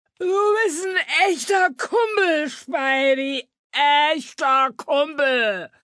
Action adventure
Roughly 50,000 words in various dialects of New York citizens were recorded just to capture the atmosphere in the city.
Fußgängerin: